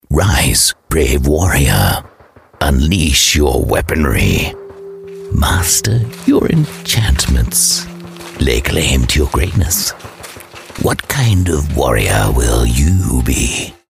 Englisch (Australisch)
Glaubhaft
Konversation
Natürlich